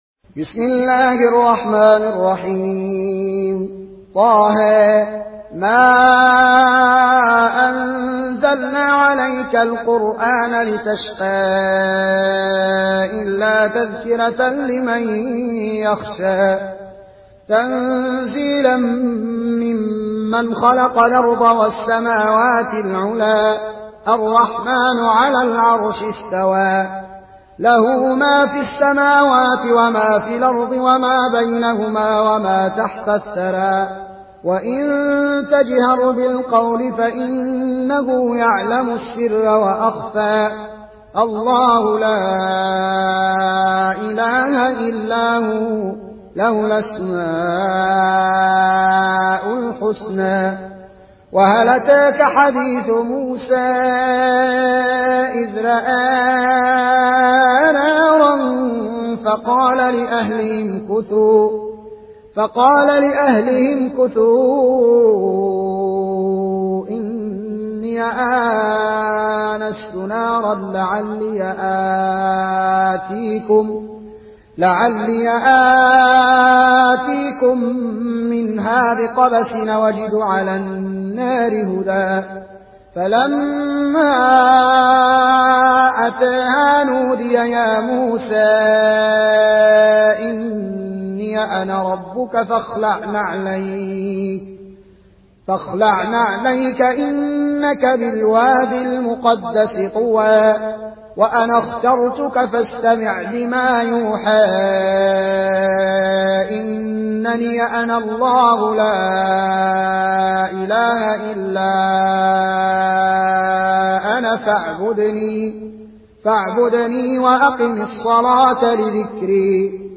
Warsh an Nafi